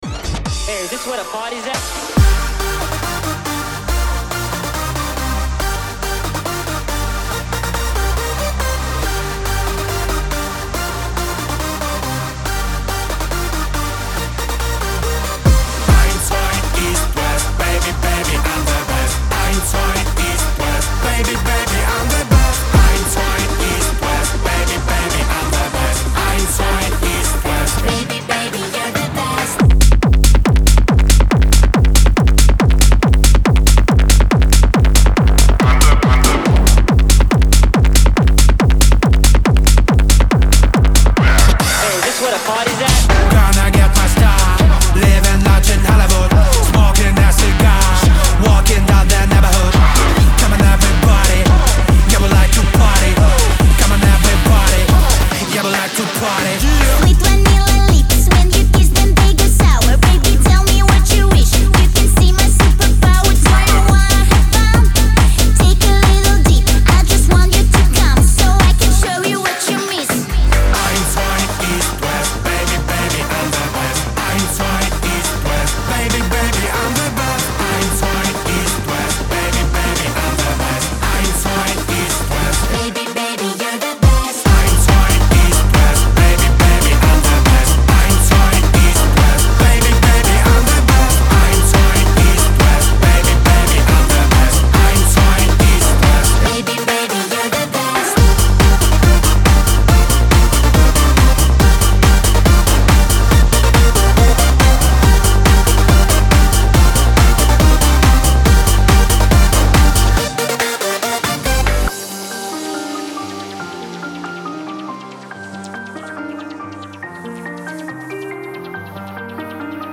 • Жанр: Pop, Electronic